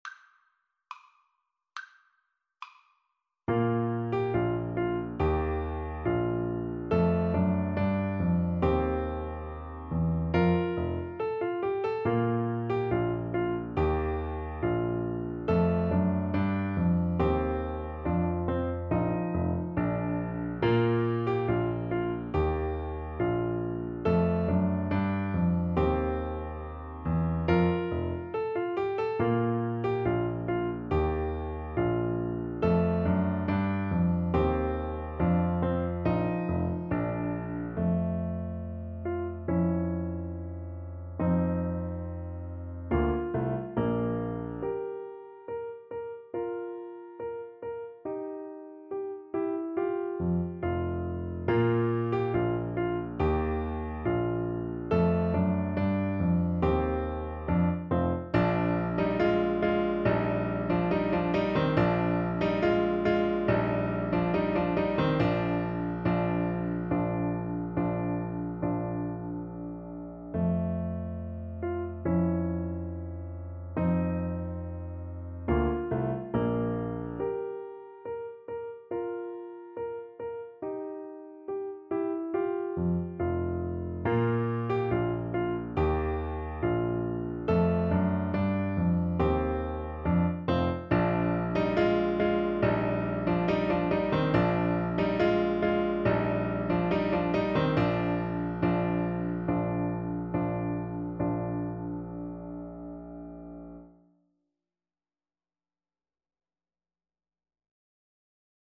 2/4 (View more 2/4 Music)
Classical (View more Classical Clarinet Music)